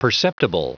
Prononciation du mot perceptible en anglais (fichier audio)
Prononciation du mot : perceptible